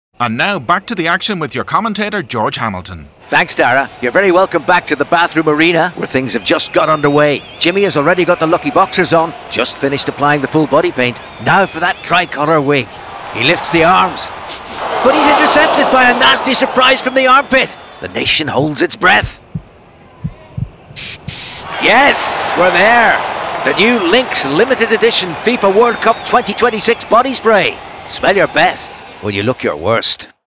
The short videos will run across social and online channels and will be supported by a 30-second digital audio piece, again featuring George Hamilton and his iconic line.
Sound Design: Tinpot Productions